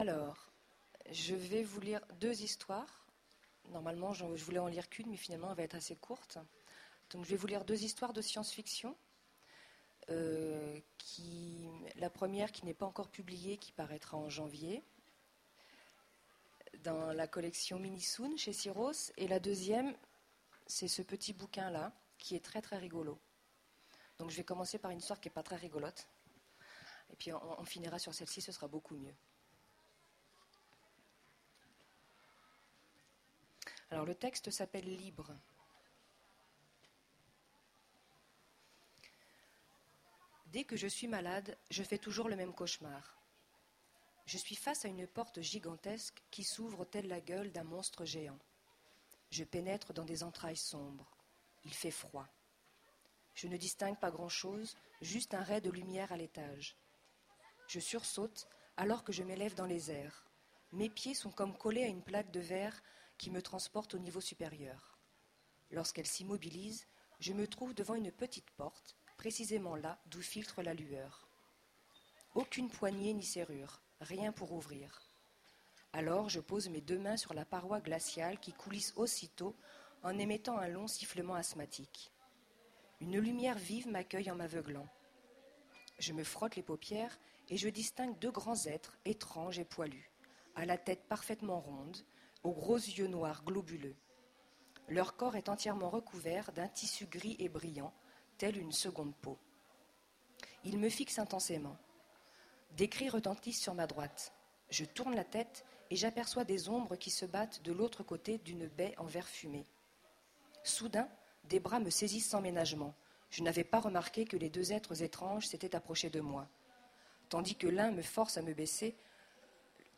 Utopiales 2010 : Lecture d'un conte
Mots-clés Rencontre avec un auteur Conférence Contes Partager cet article